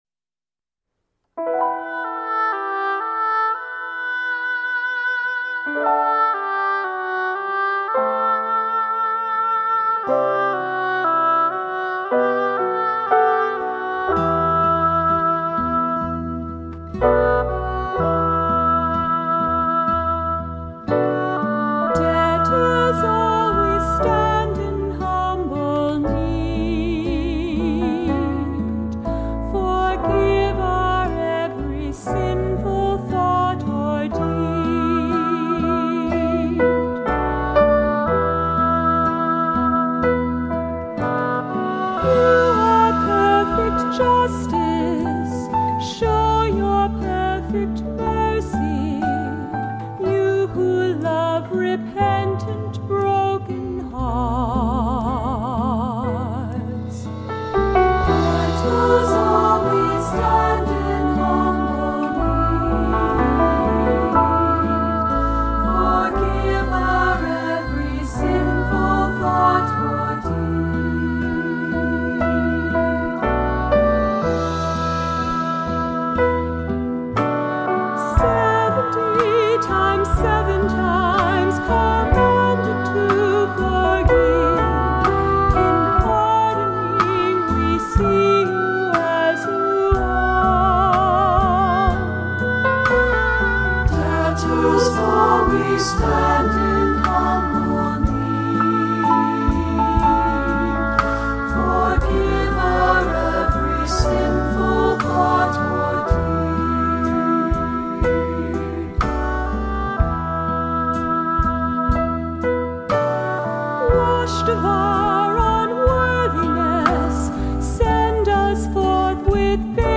Voicing: Assembly, cantor, descant,SATB